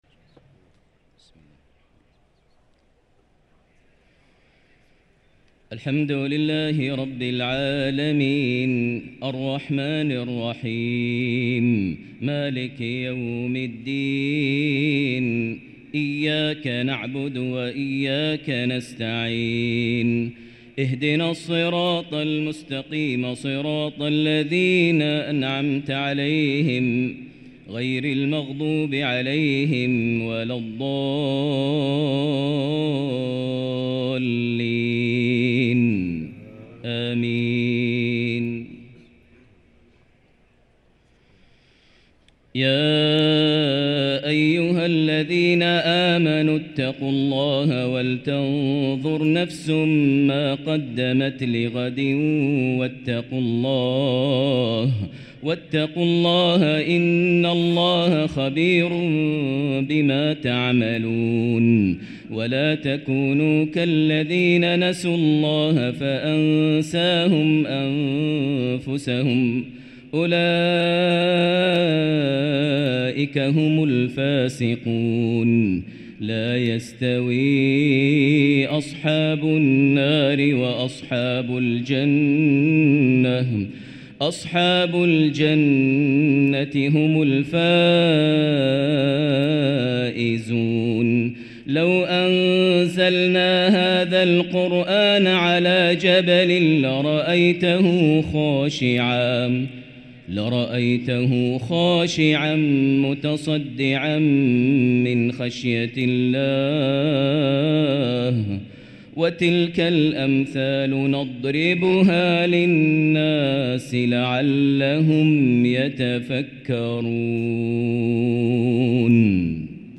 lsha prayer from Al-Hashr 8-4-2023 > 1444 H > Prayers - Maher Almuaiqly Recitations